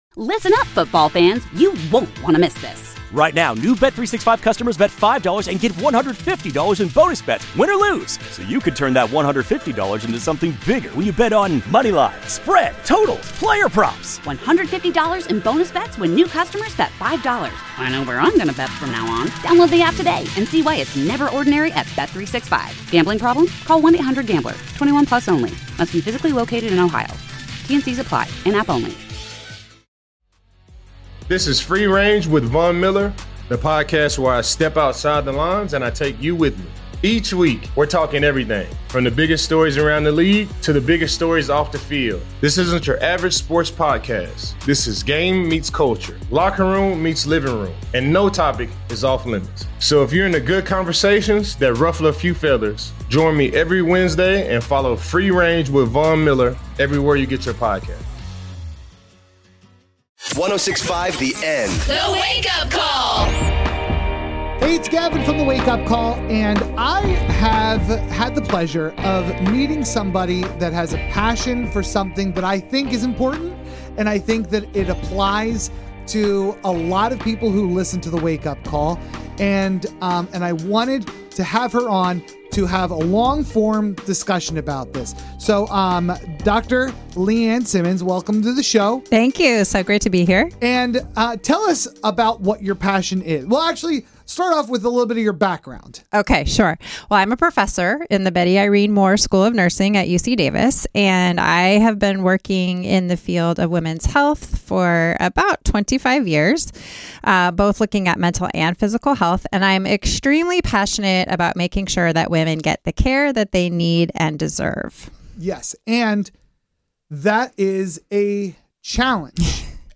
The Wake Up Call is a morning radio show based in Sacramento, California, and heard weekday mornings on 106.5 the End.